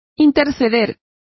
Complete with pronunciation of the translation of pleaded,pled.